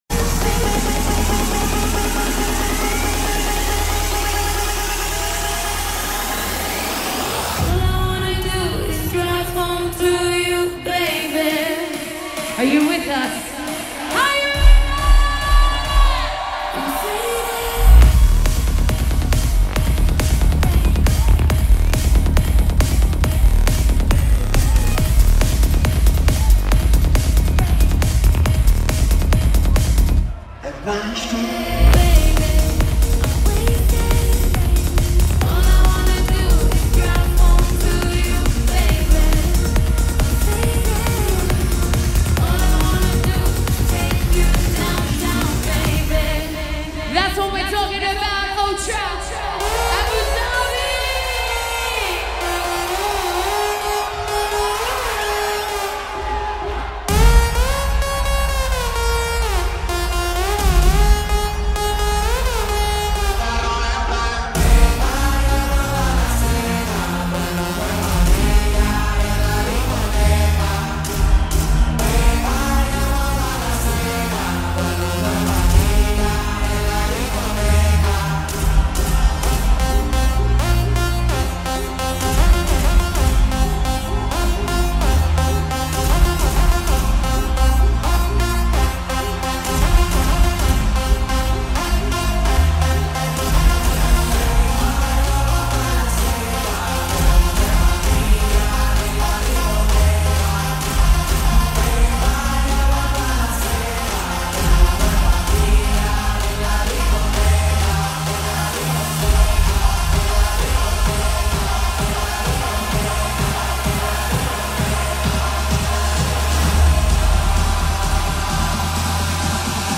Also find other EDM Livesets, DJ Mixes and Radio Show